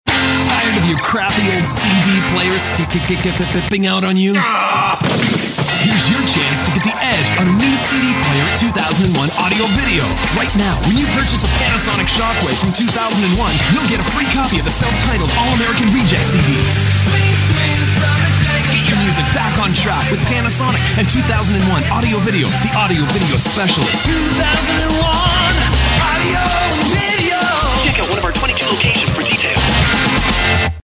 Commercial jingle plus voiceover